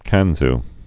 (kănz)